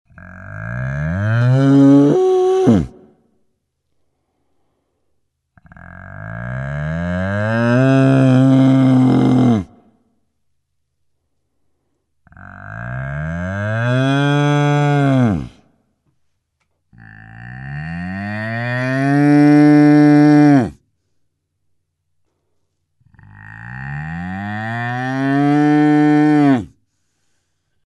Звуки быка
Глухой звук рычания быка